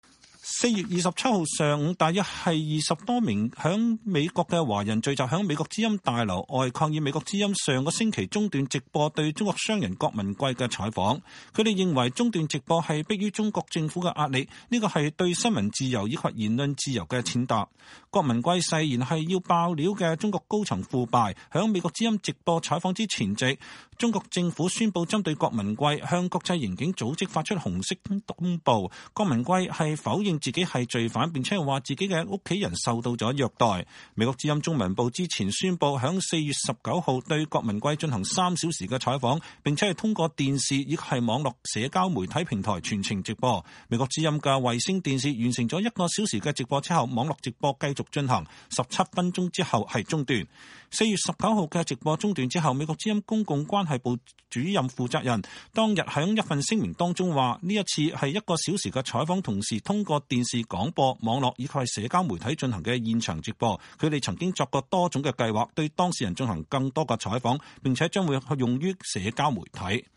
在美華人4月27日在美國之音總部抗議郭文貴直播中斷